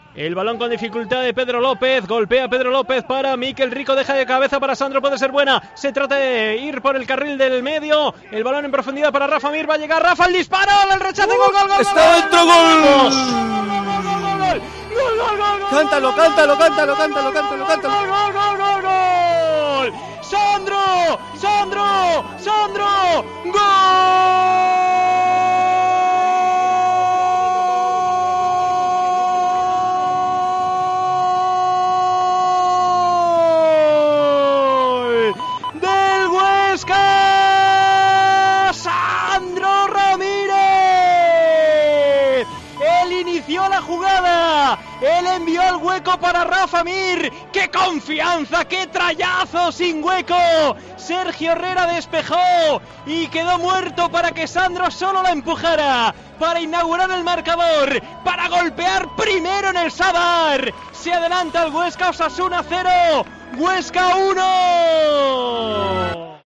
Narración Gol de Sandro / Osasuna 0-1 Huesca